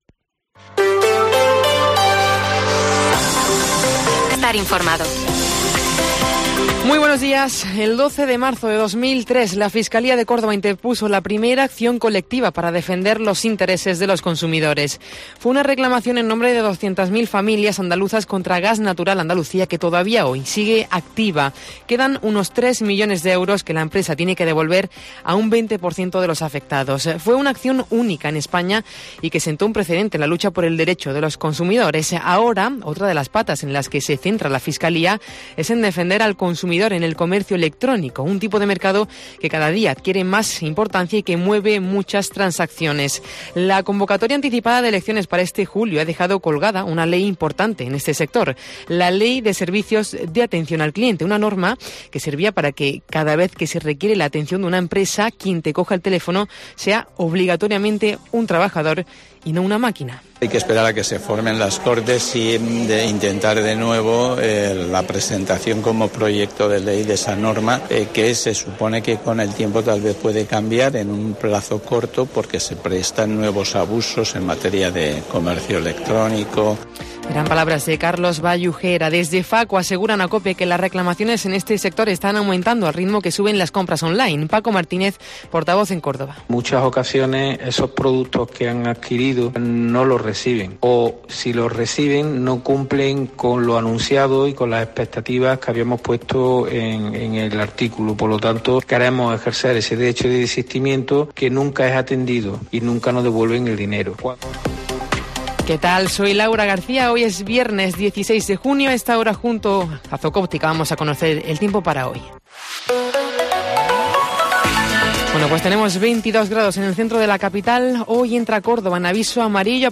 Informativo